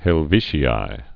(hĕl-vēshē-ī)